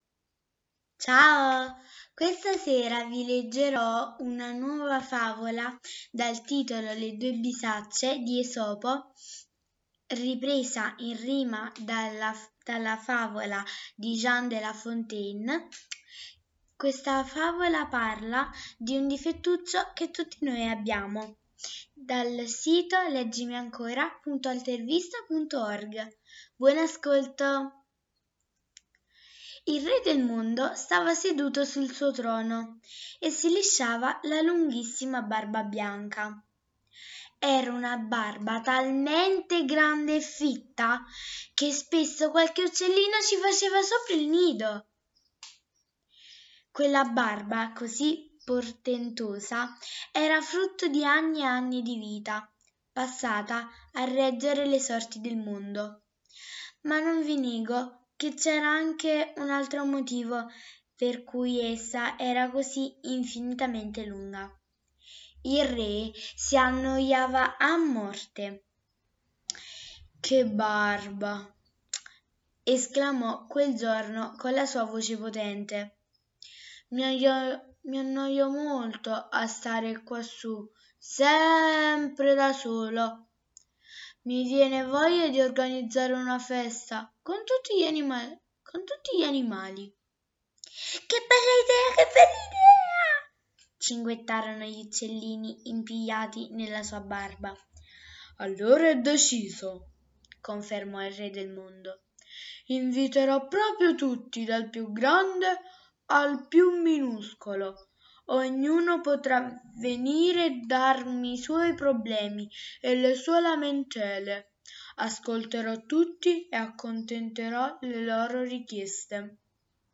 Le due bisacce | Questa sera vi leggo una favola di Esopo " Le due bisacce", come sempre dice a tutti un grande insegnamento.